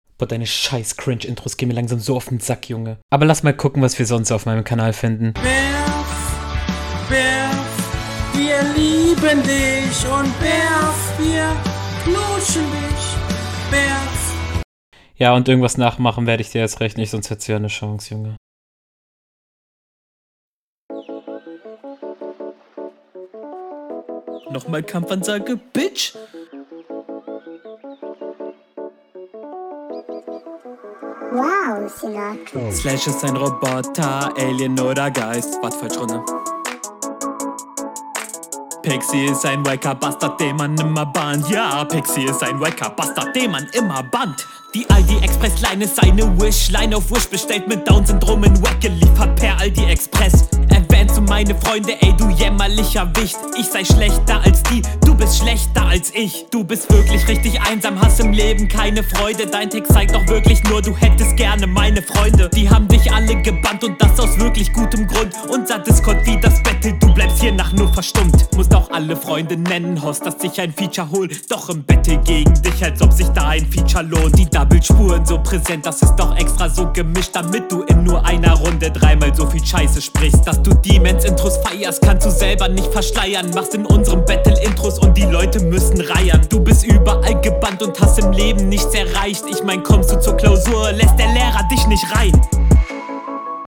Flow: Auch hier deutlich an dich auch wenn deine Hook weh tut in den Ohren …